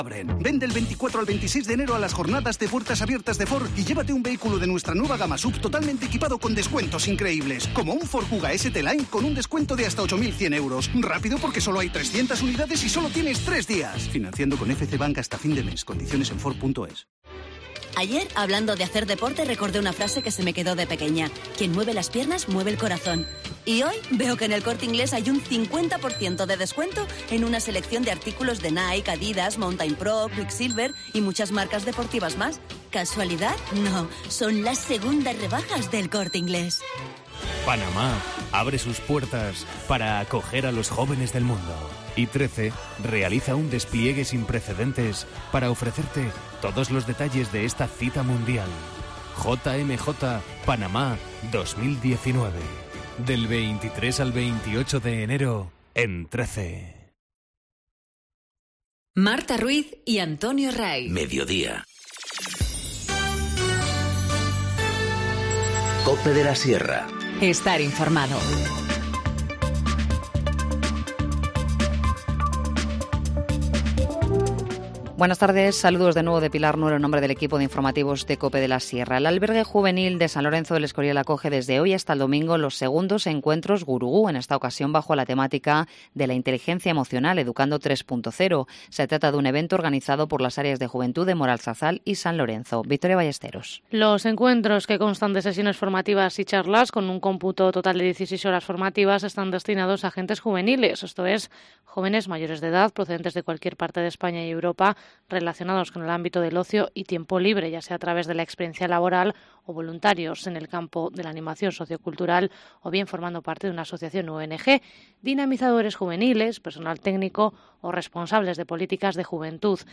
Informativo Mediodía 25 enero- 14:50h